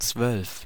Ääntäminen
Synonyymit Dutzend Zwölf Ääntäminen Tuntematon aksentti: IPA: [t͡svœlf] IPA: /ˈtsvœlf/ Haettu sana löytyi näillä lähdekielillä: saksa Käännöksiä ei löytynyt valitulle kohdekielelle.